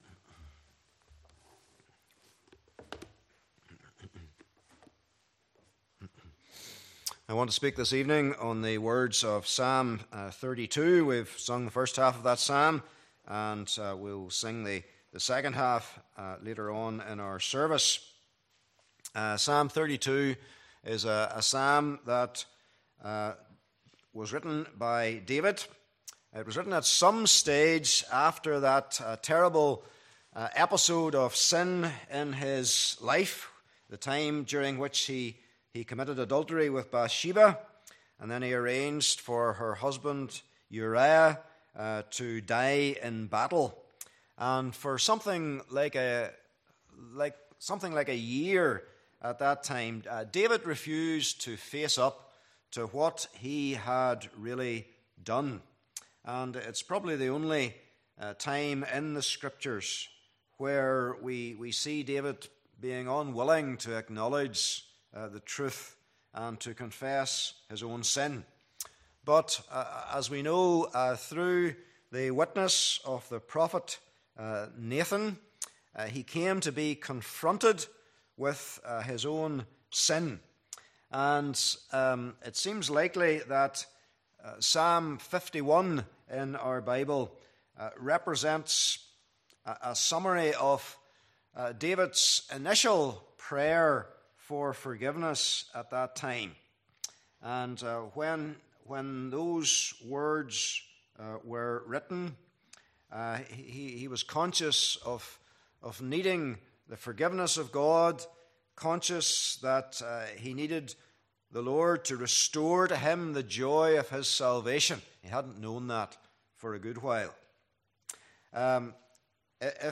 11 Service Type: Evening Service Bible Text